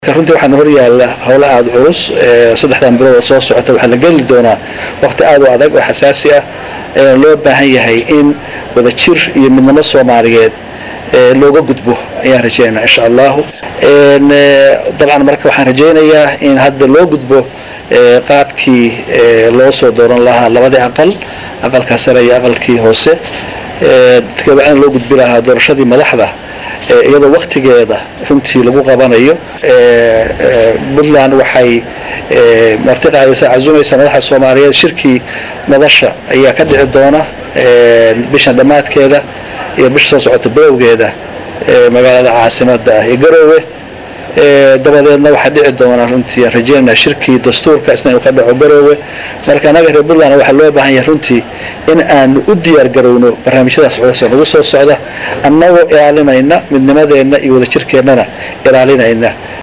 Garowe(INO)_Madaxweynaha Maamulka Puntland C/weli  Maxamed Cali Gaas oo warbaahinta la hadlay ayaa ka hadlay doorashooyinka la filayo in sanadkaan ay ka dhacaan Soomaaliya.